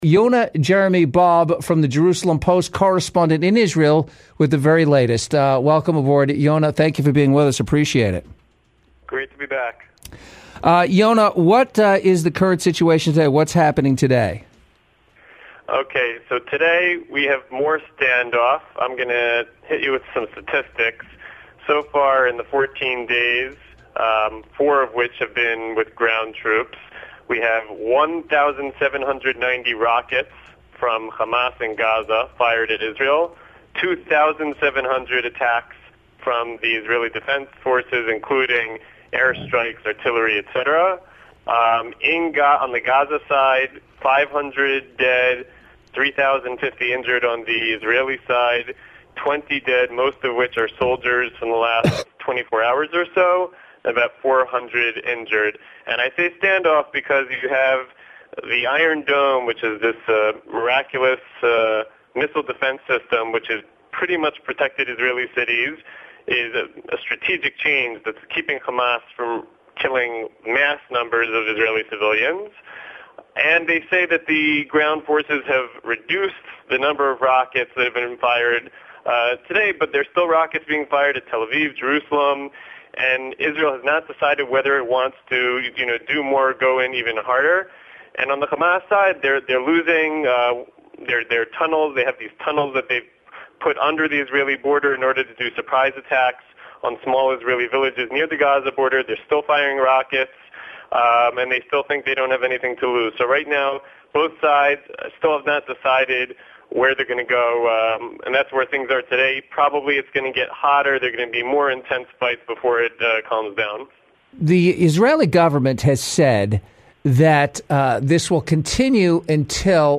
1st ABC Los Angeles Radio Interview